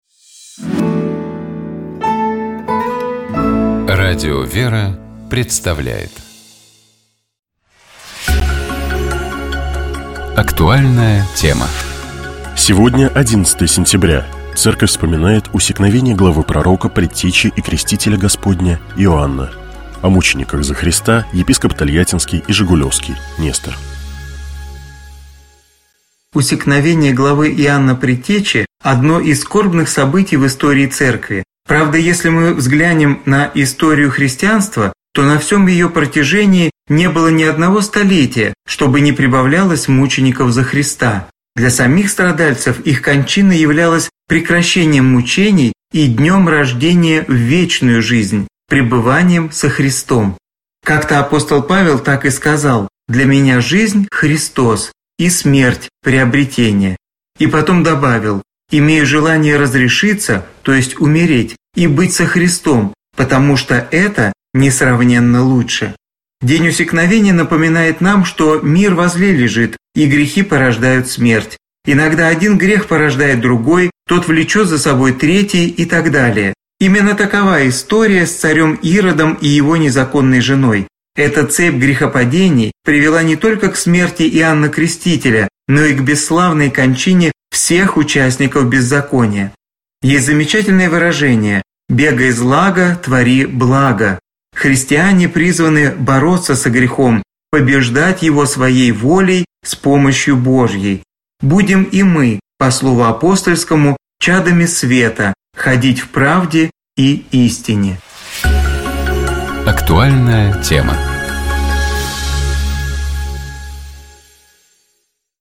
О мучениках за Христа, — епископ Тольяттинский и Жигулёвский Нестор.